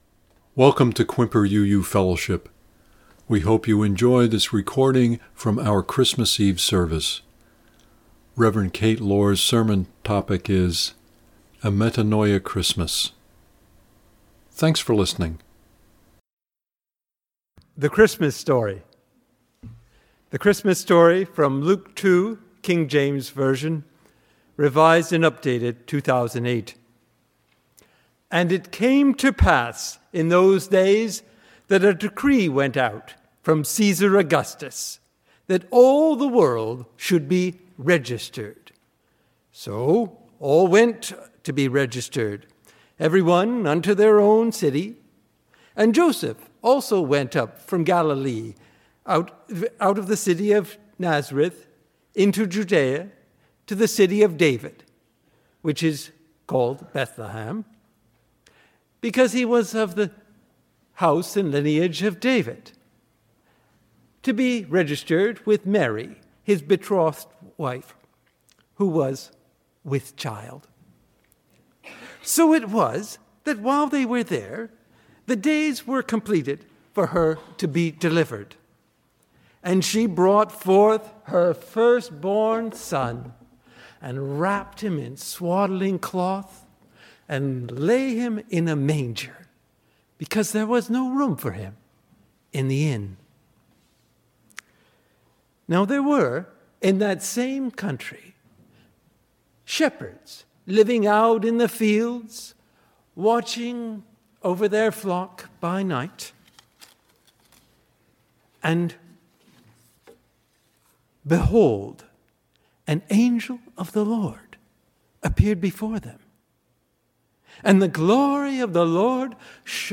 Christmas Eve Candlelight Service
Click here to listen to the reading and sermon.